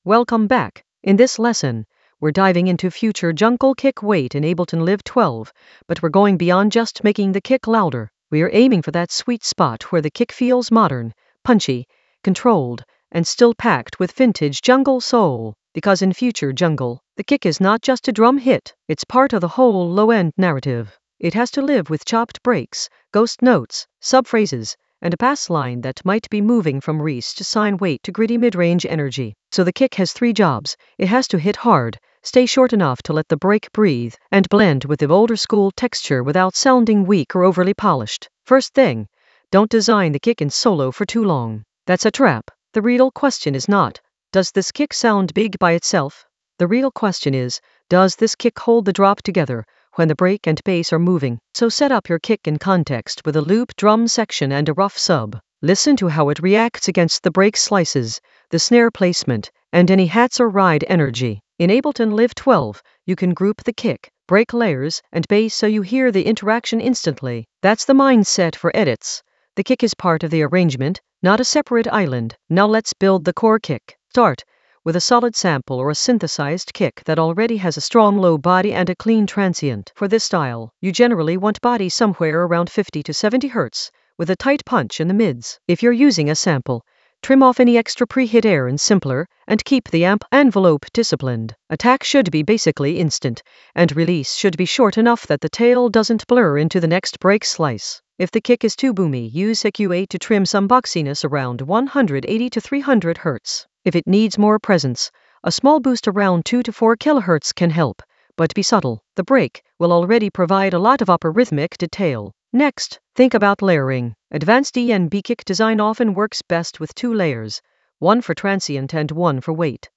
An AI-generated advanced Ableton lesson focused on Future Jungle Ableton Live 12 kick weight guide with modern punch and vintage soul in the Edits area of drum and bass production.
Narrated lesson audio
The voice track includes the tutorial plus extra teacher commentary.